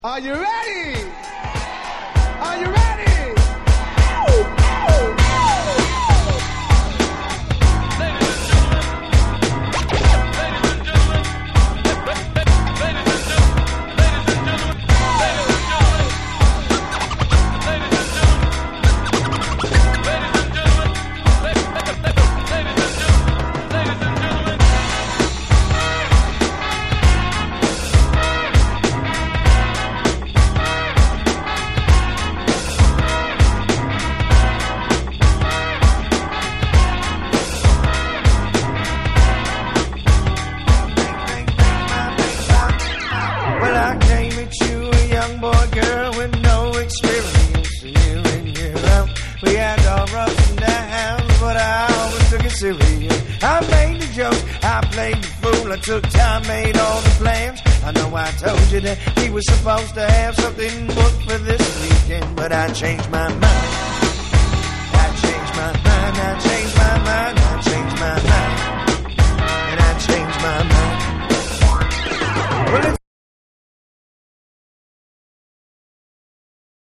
FUNK色濃い未だ人気の絶えない1枚！
BREAKBEATS